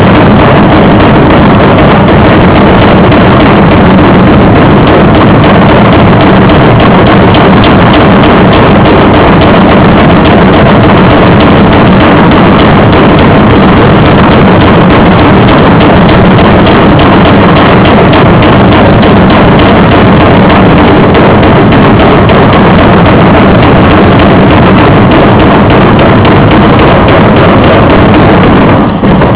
Le martinet hydraulique / The hydraulic tilt hammer
Une batterie de martinets hydrauliques est formée d'une roue à augets qui entraîne deux roues à cames mettant en mouvement deux marteaux. Ceux-ci frappent le métal rouge sortant du four.
They hit the red metal just removed from the furnace.